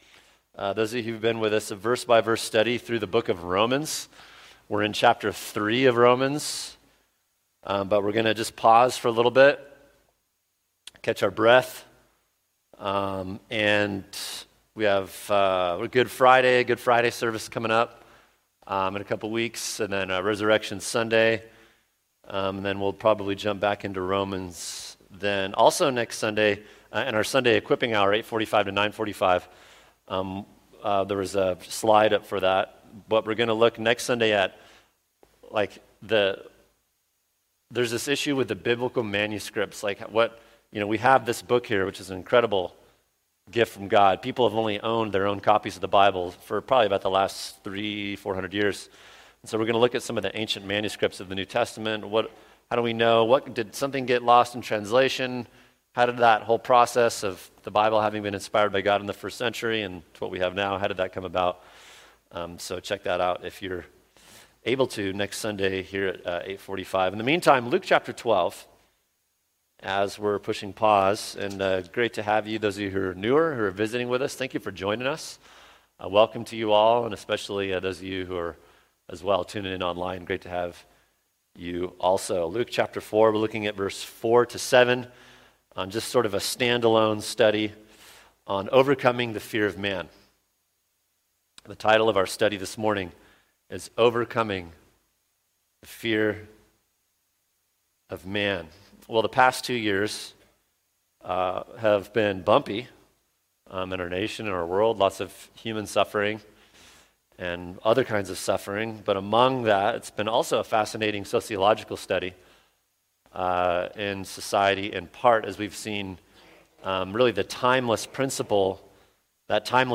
[sermon] Luke 12:4-7 Overcoming the Fear of Man | Cornerstone Church - Jackson Hole